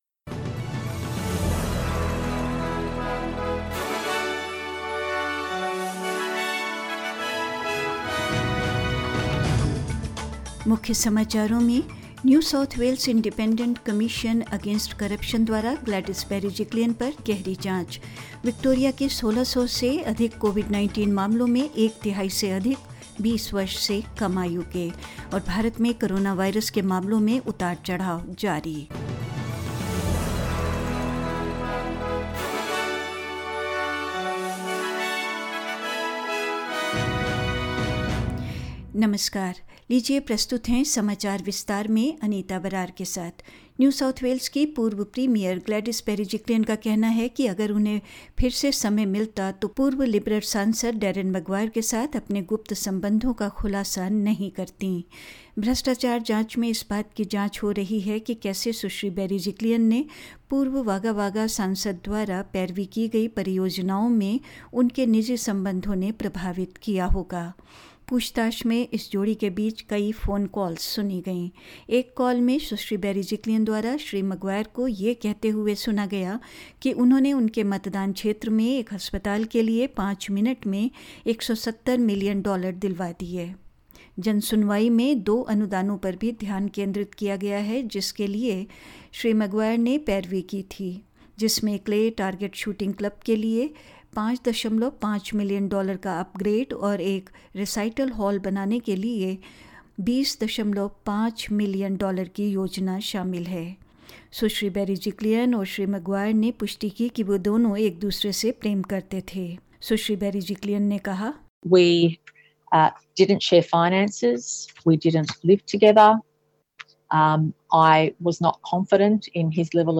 In this latest SBS Hindi News bulletin of Australia and India: Gladys Berejiklian grilled by the New South Wales Independent Commission Against Corruption; More than a third of Victoria's more than 1600 COVID-19 cases are under the age of 20; India reported less than 15K COVID-19 cases with recovery rate at 98.19 per cent and more news